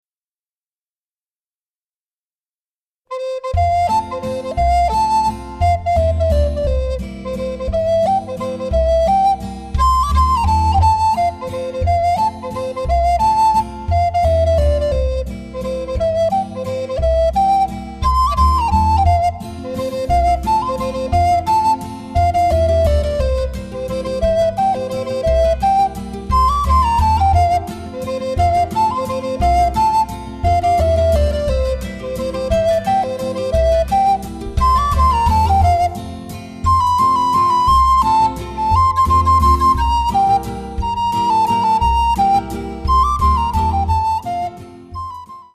12 Vibrant themes with Latin flavour and spirit
Obsazení: Alt-Blockflöte